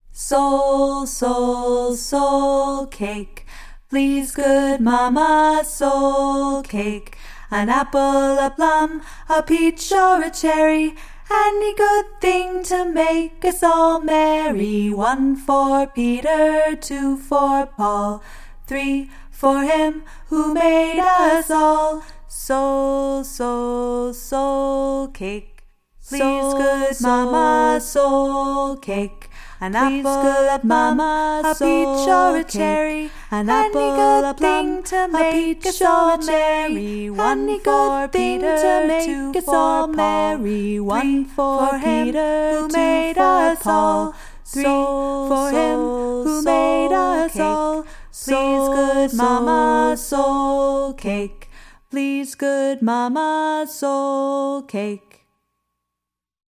five part English round